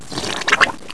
squelch3.wav